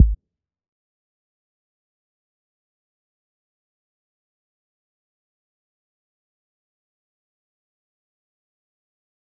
JJKicks (18).wav